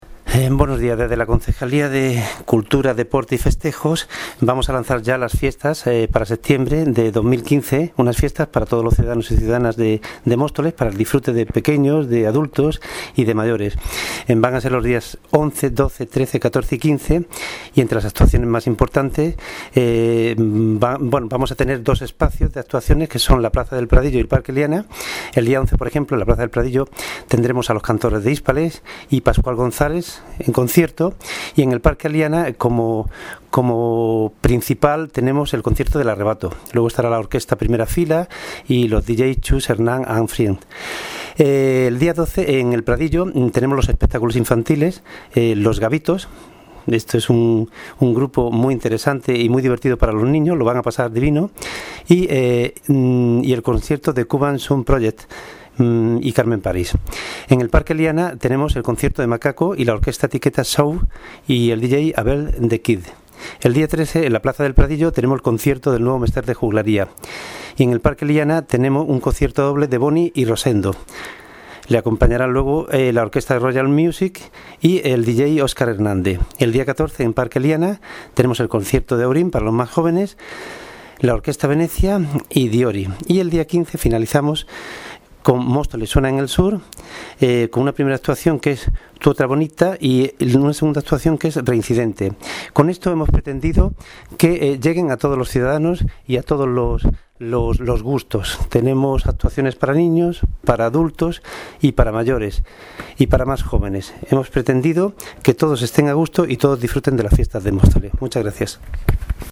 Audio - Agustín Martín (Concejal de Cultura, Festejos y Deporte) Sobre Fiestas Septiembre